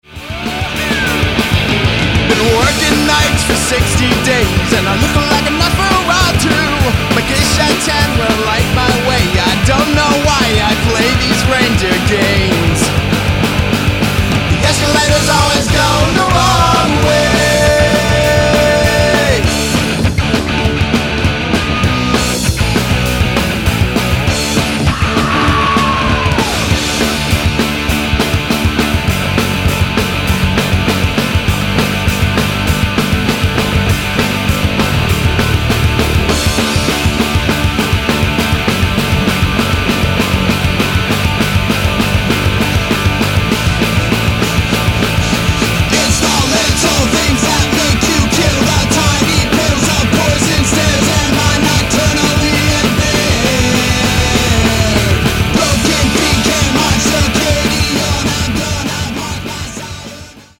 rock album